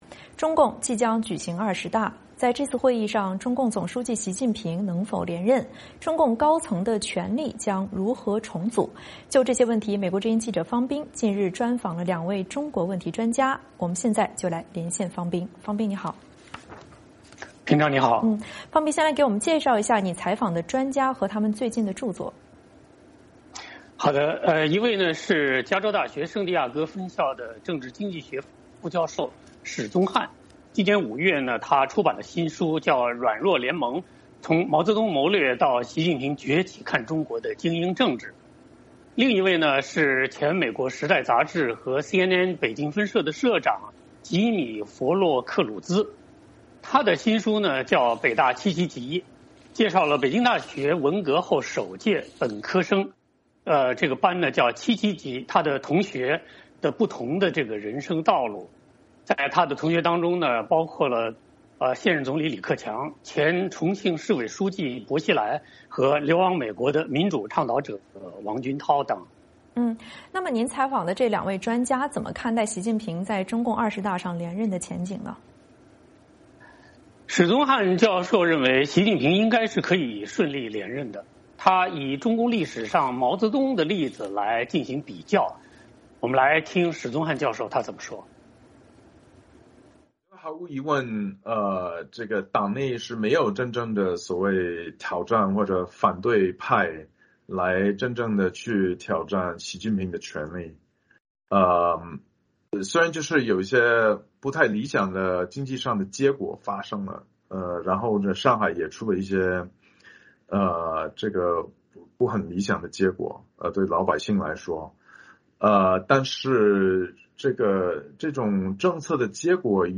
最近美国之音记者专访了两位中国问题专家，谈论了这些问题。